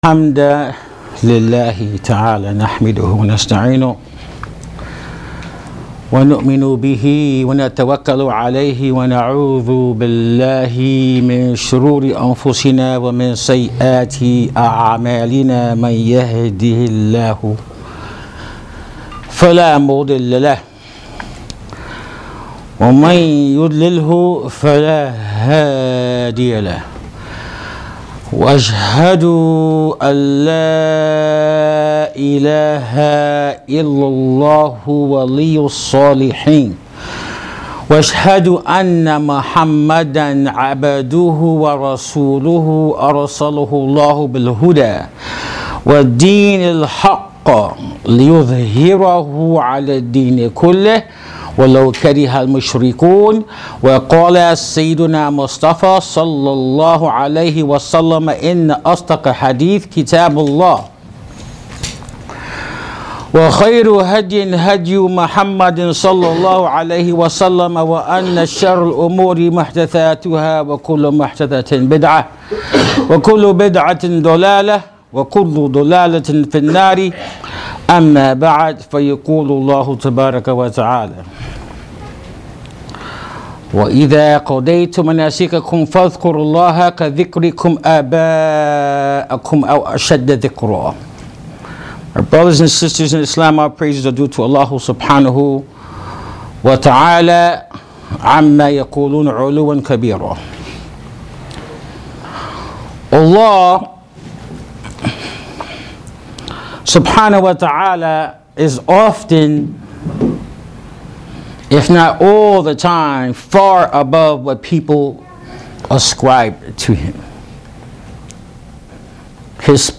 Audio Khutba: The Keys to Mental and Emotional Health from The Quran and the Sunna, Part Three